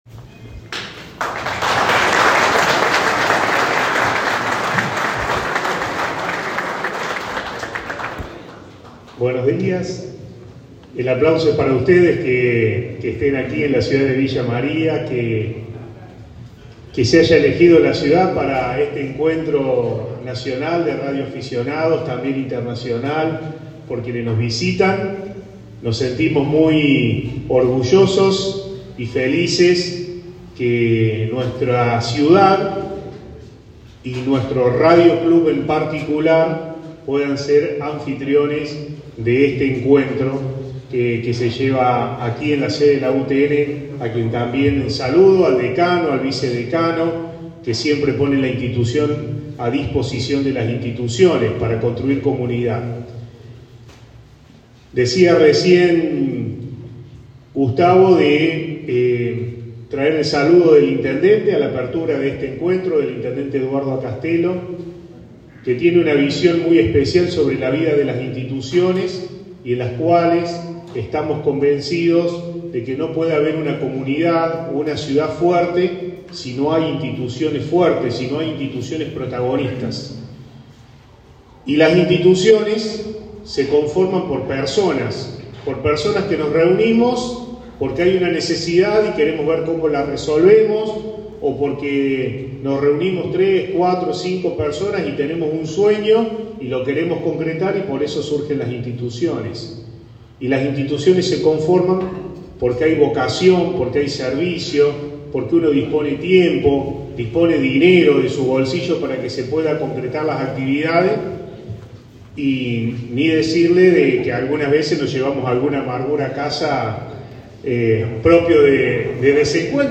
Villa María, 12 de octubre de 2024 – Con un acto inaugural que reunió a autoridades locales, organizadores y referentes del mundo de la radioafición, comenzó oficialmente el Encuentro de Radioaficionados Villa María 2024 en la Facultad Regional Villa María de la Universidad Tecnológica Nacional (UTN).